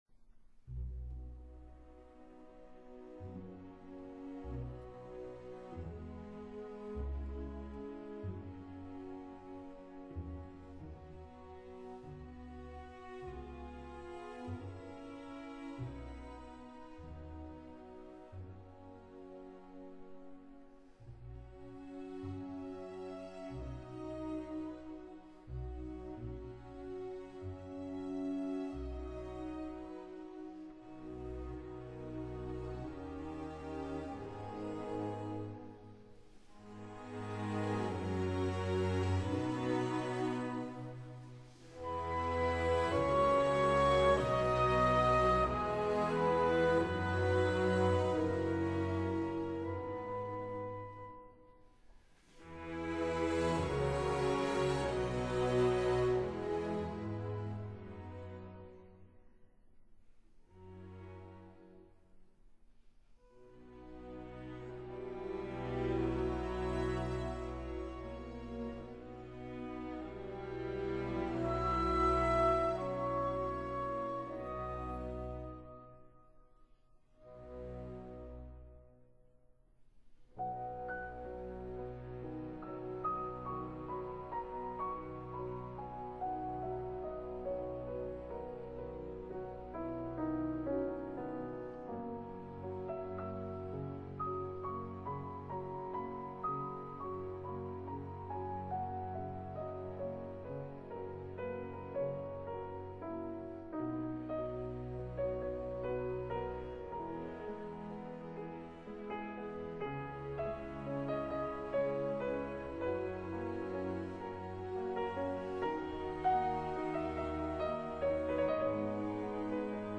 鋼琴&指揮
●2014年第六十九屆的「布拉格之春」音樂會的演出記錄。
●於音響效果優異的布拉格魯道夫音樂廳（Rudolfinum）之德弗乍克廳（Dvorak Hall）完成錄音。